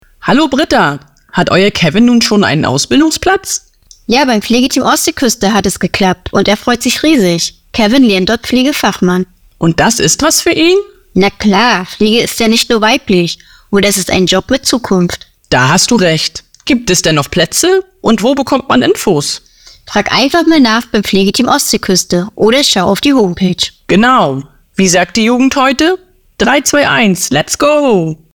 Radio-Spot